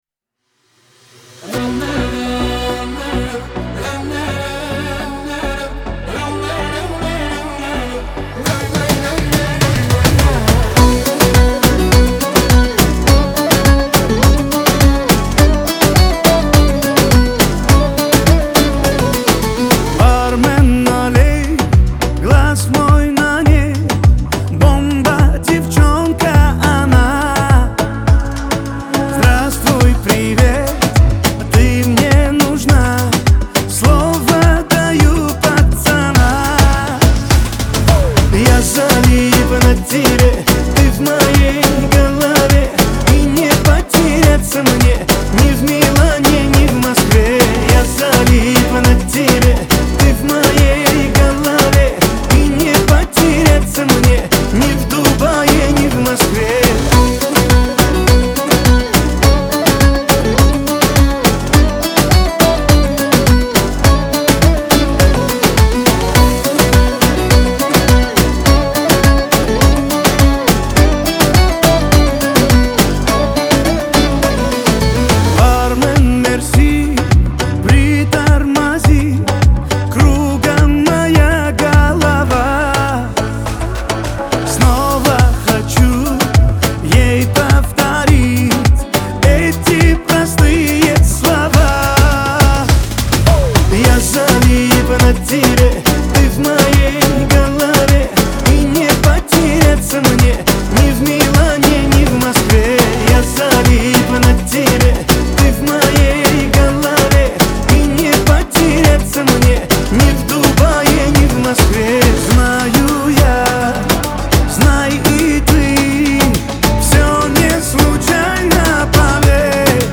Лирика
Кавказ – поп